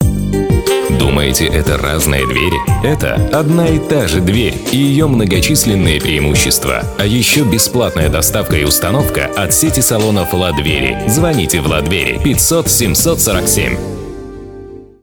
Native speaker Male 30-50 lat
Nagranie lektorskie